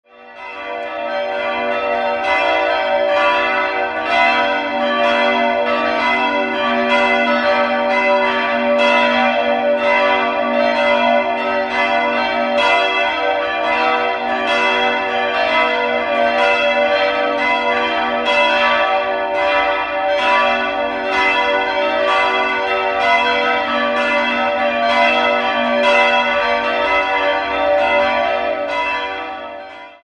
Idealquartett: h'-d''-e''-g''
Christusglocke h'+0
Tauf- und Sterbeglocke g''+2 90 kg 52 cm 1993 Bachert
bell
Dieses Geläute ist auf das der kath. Stadtpfarrkirche abgestimmt.